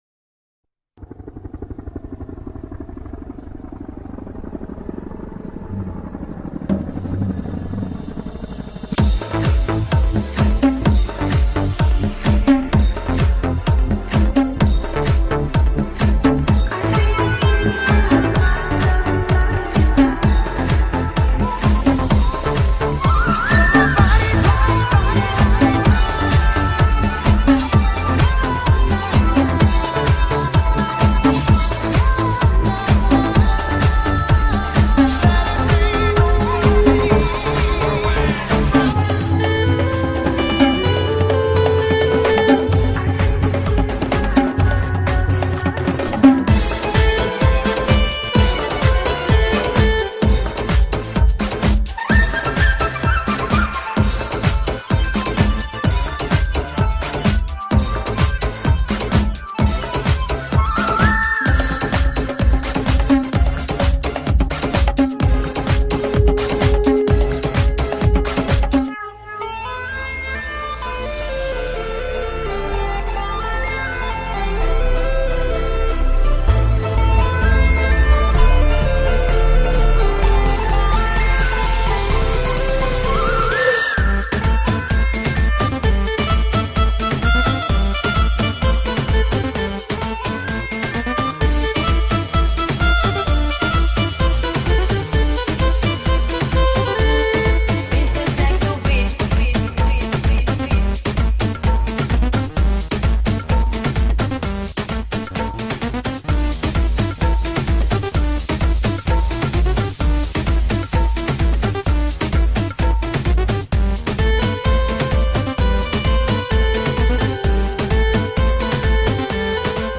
SL Traditional Drum Mix
Remix